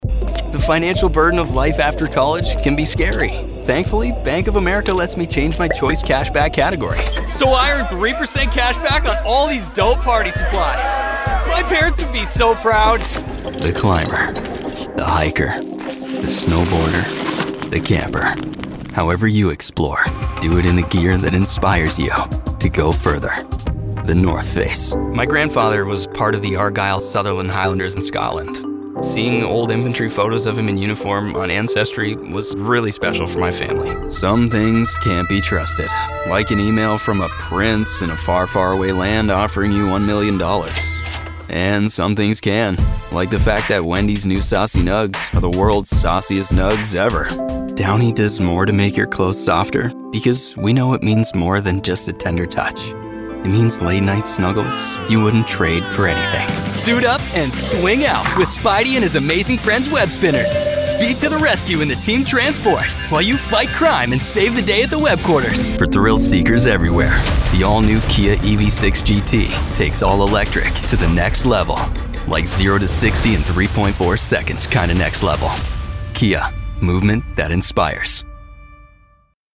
Commercial Demo Download Character Demo Download IVR Demo Download Bring your brand’s message to life!